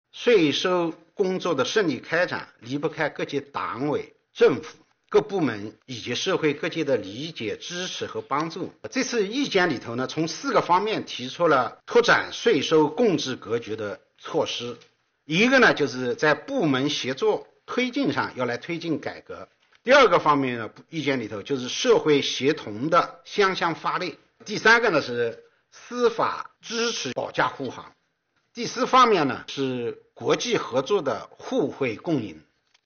近日，国务院新闻办公室举行新闻发布会，国家税务总局相关负责人介绍《关于进一步深化税收征管改革的意见》（以下简称《意见》）有关情况。会上，国家税务总局党委委员、副局长任荣发表示，税收工作的顺利开展，离不开各级党委政府、各部门及社会各界的理解、支持和帮助，《意见》从四个方面提出了拓展税收共治格局的措施。